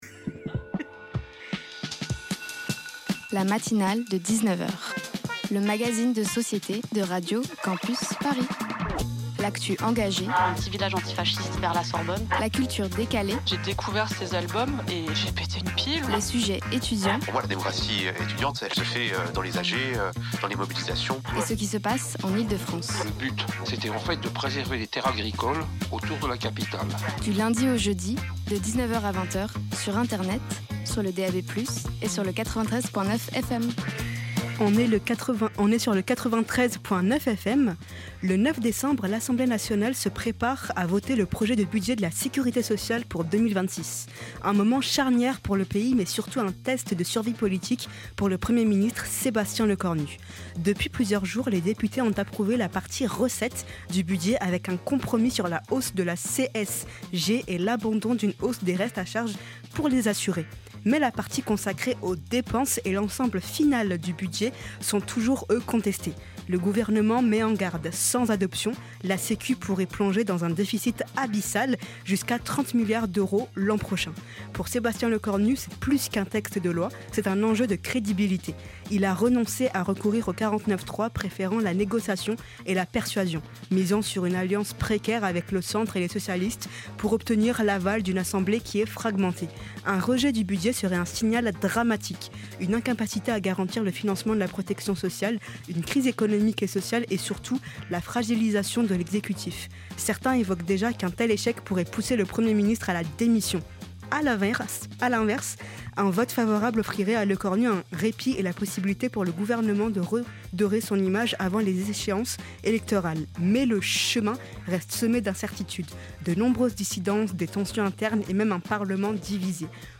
Type Magazine Société Culture